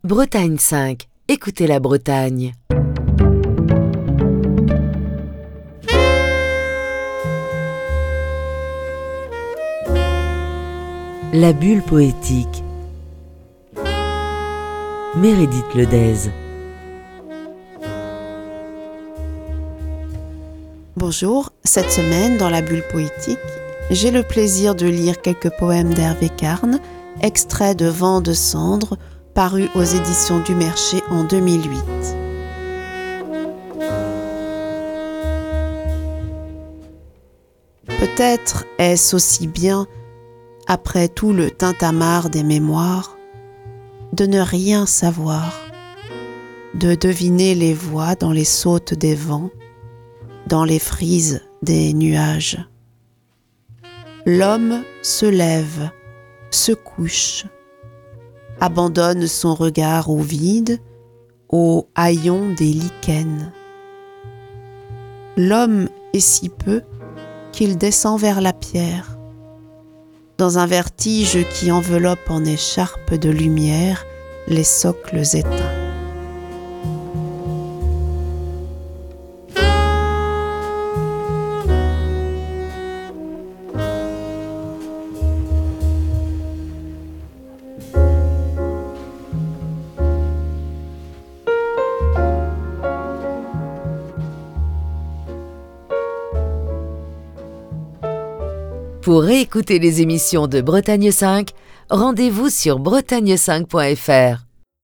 la lecture de textes